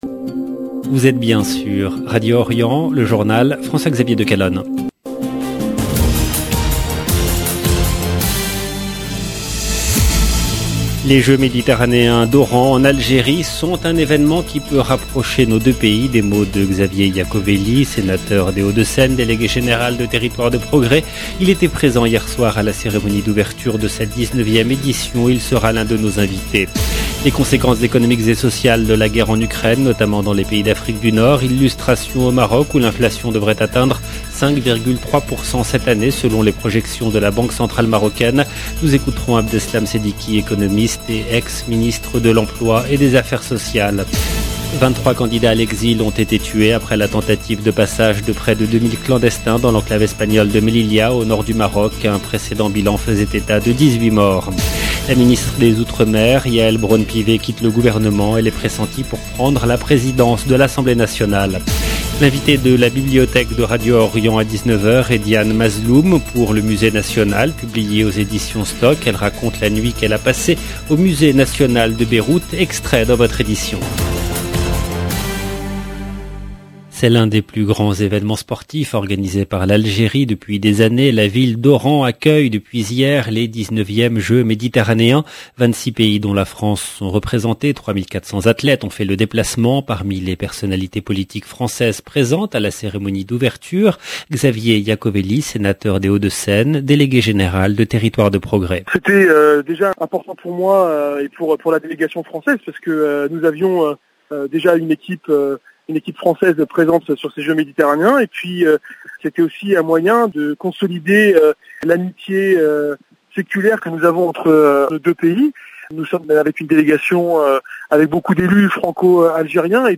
EDITION DU JOURNAL DU SOIR DU 26/6/2022